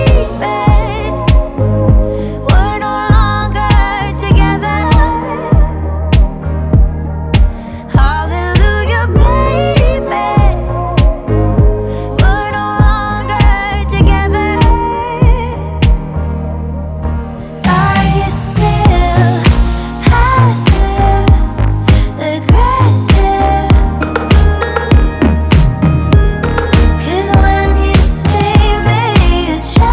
• Vocal